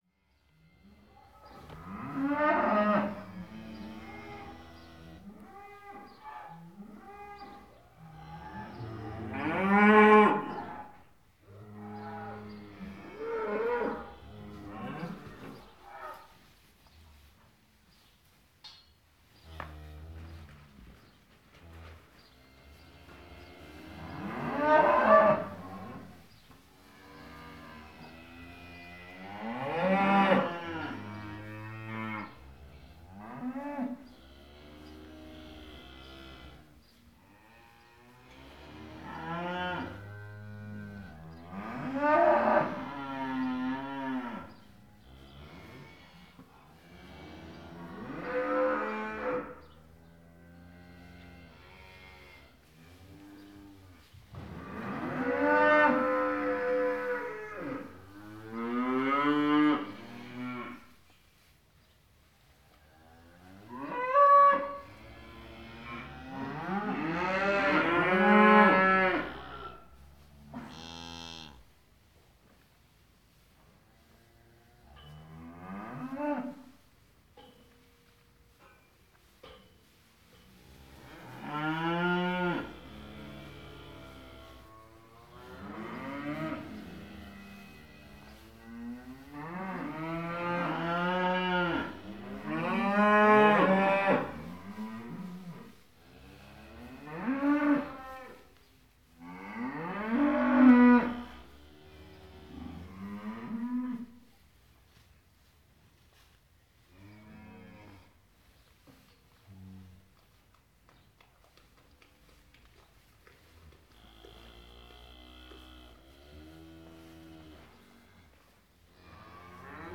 SND_cow_long.ogg